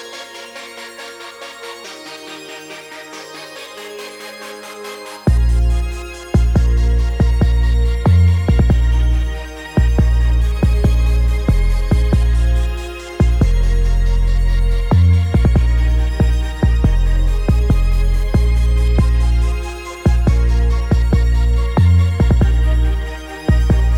no Backing Vocals with wailing R'n'B